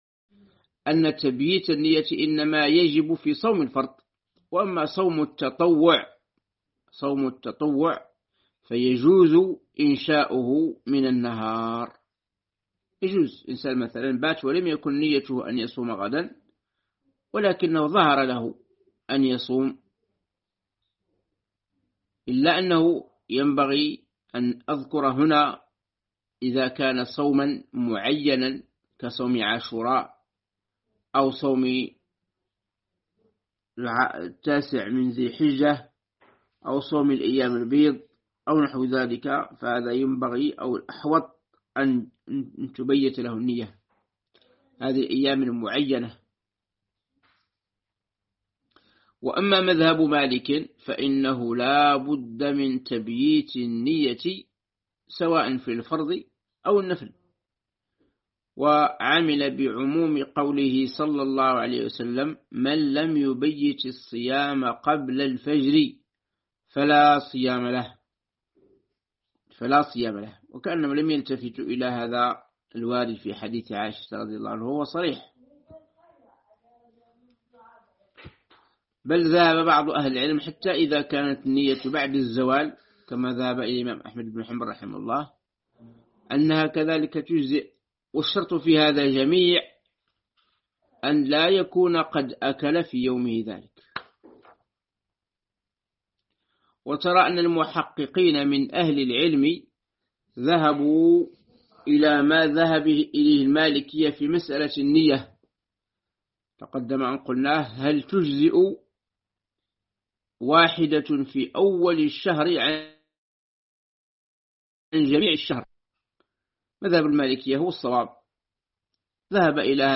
شرح